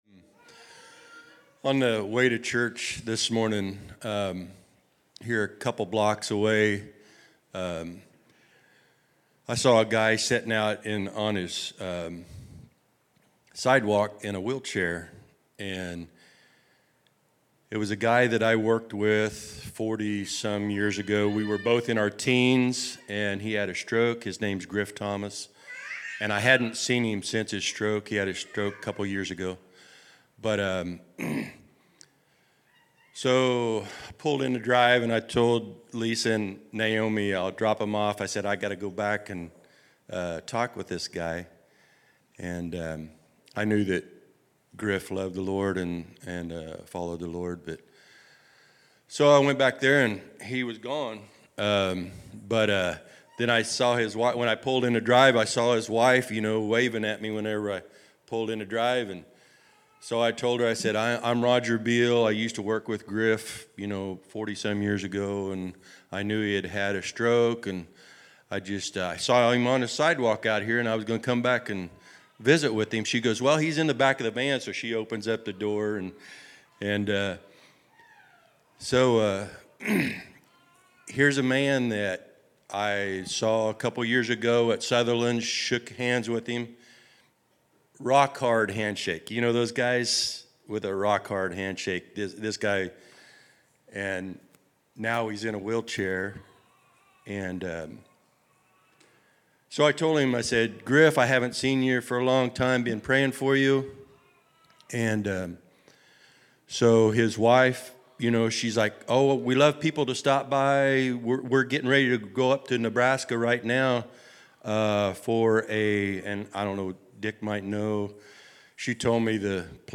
Prayer Requests